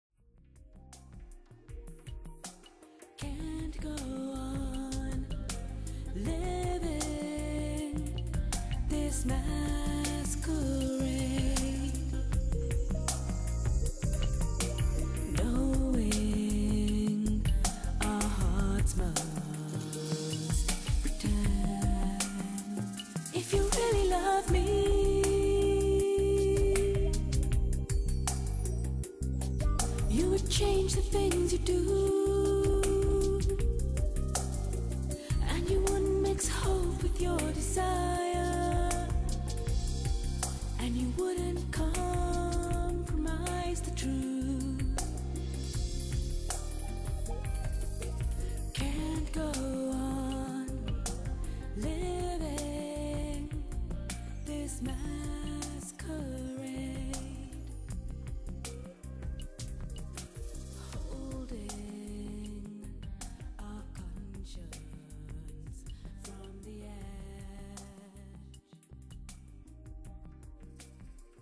∼本專輯非常有巧技地融入了當代爵士樂、節奏藍調、並帶有歐洲與拉丁的風味。